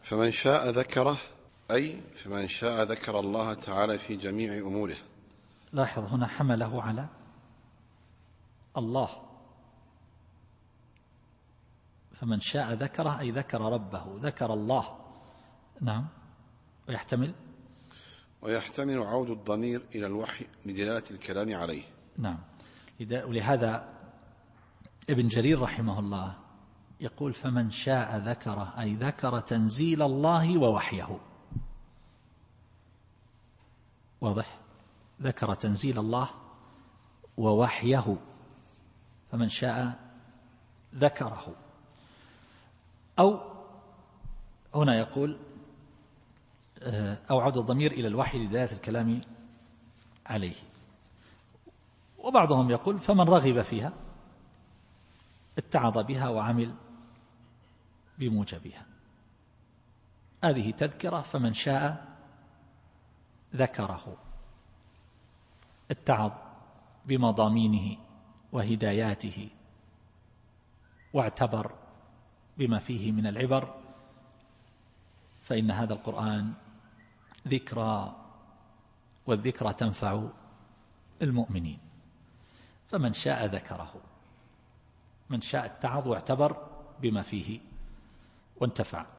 التفسير الصوتي [عبس / 12]